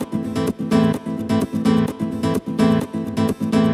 VEH3 Nylon Guitar Kit 2 - 2 A min.wav